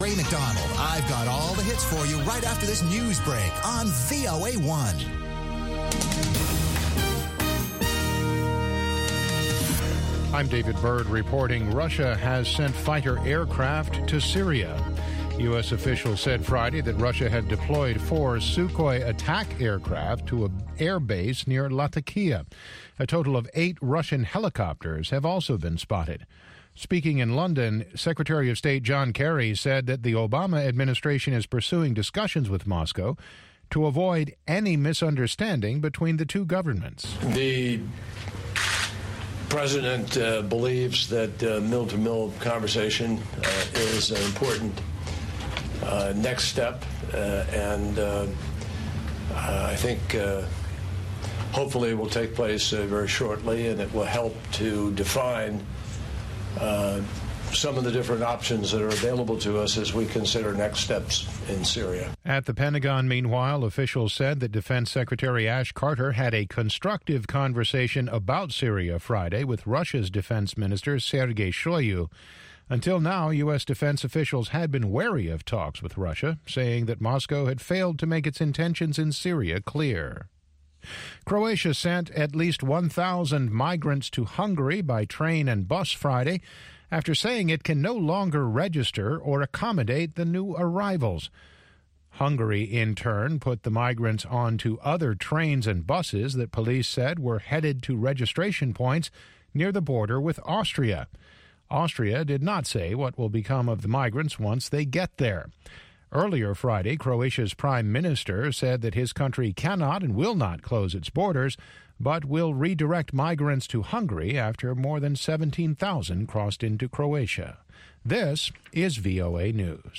from N’dombolo to Benga to African Hip Hop
the best mix of pan-African music